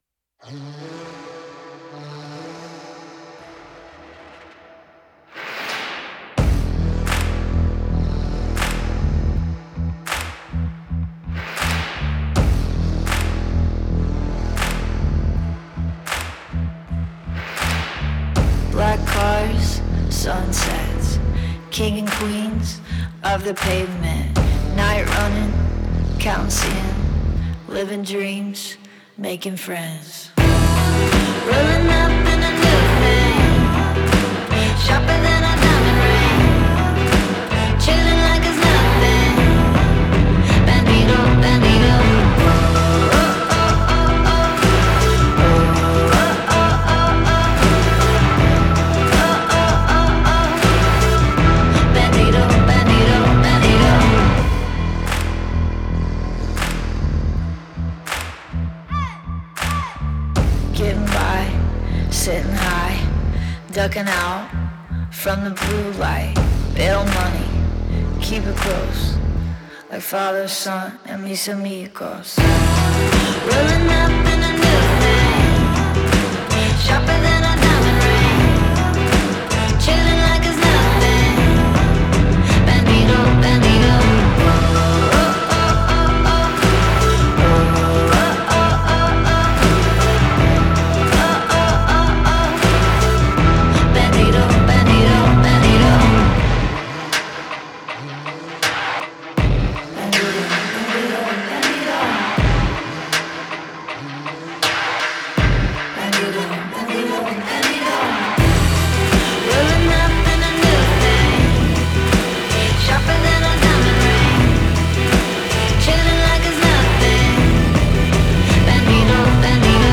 latin fusion